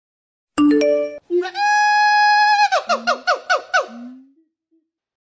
Nada notifikasi Siamang Monkey
Genre: Nada dering binatang
Suara khas siamang, salah satu jenis monyet hutan yang suaranya nyaring dan lucu, dijamin bikin HP kamu beda dari yang lain.
nada-notifikasi-siamang-monkey.mp3